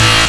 gtdTTE67028guitar-A.wav